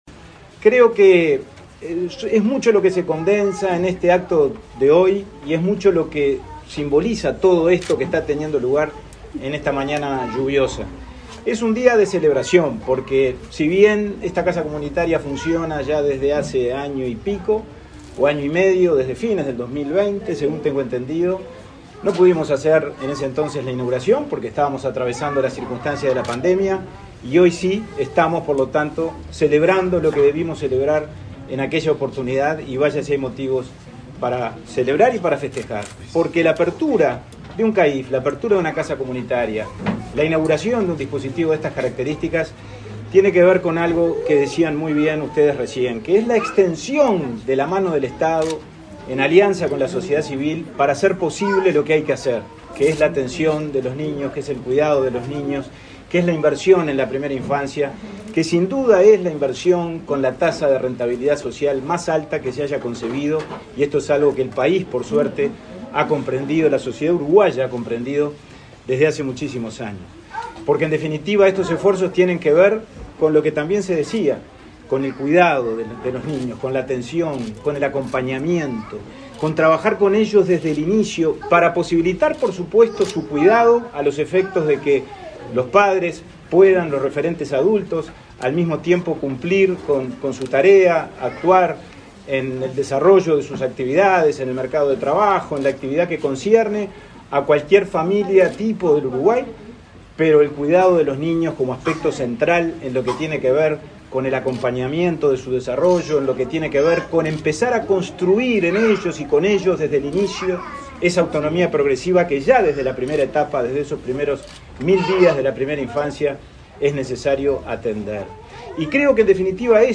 Palabras del presidente de INAU y del titular del Mides